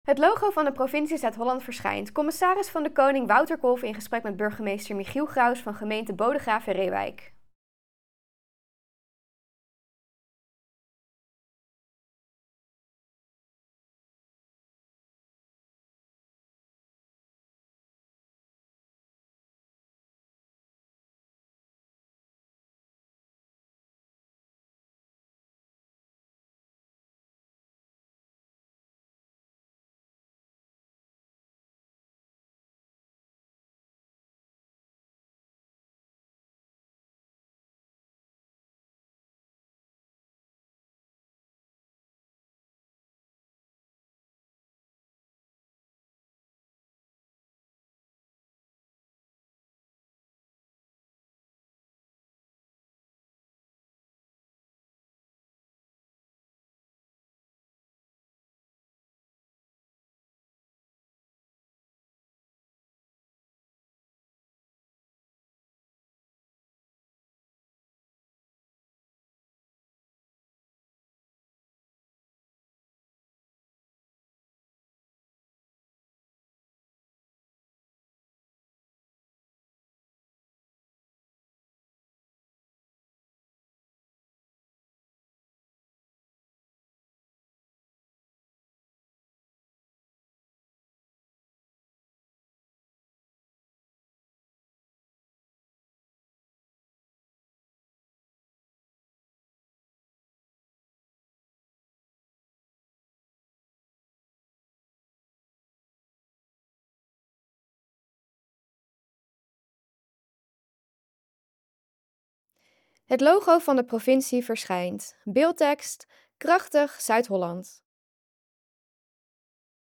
CdK in gesprek met burgemeester Bodegraven-Reeuwijk
De commissaris van de Koning bezoekt de komende tijd alle 50 gemeenten van Zuid-Holland. In deze video gaat hij in gesprek met de burgemeester van Bodegraven-Reeuwijk.